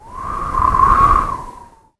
그렇다면 아름다운 풍경에서 바람을 맞는 소리를 만들고 또, SORA 1을 통해 아름다운 풍경을 만들어 함께 감상하는 시간을 가지면 정말 좋을 것 같습니다.